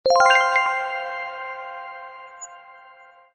lb_prompt_sound_deal.mp3